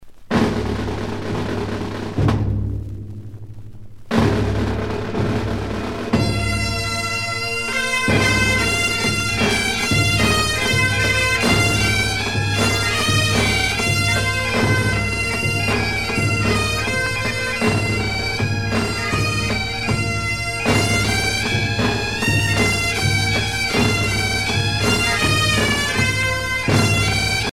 gestuel : à marcher
circonstance : fiançaille, noce
Pièce musicale éditée